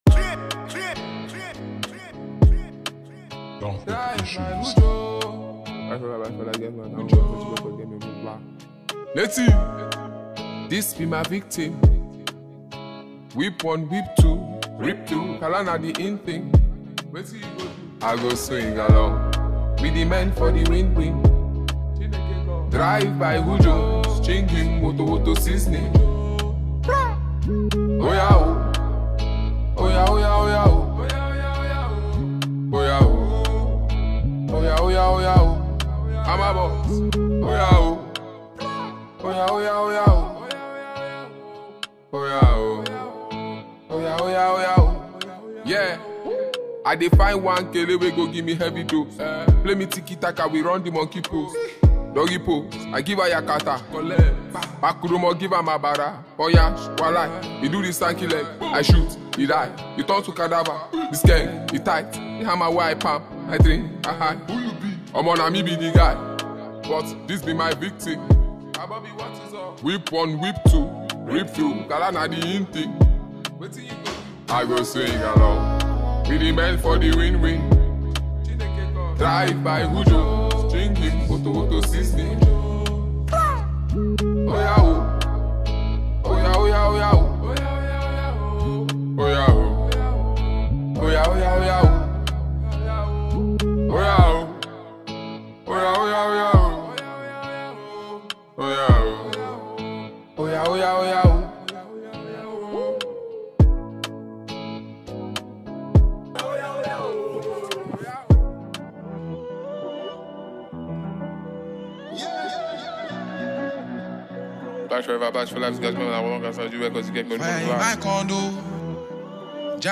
Phenomenon talented Nigerian rap artist and performer
catchy song